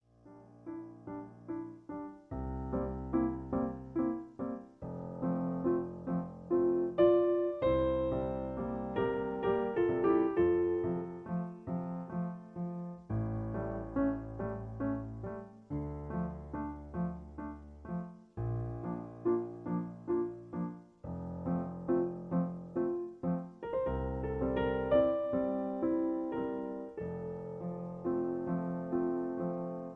Original key (D). Piano Accompaniment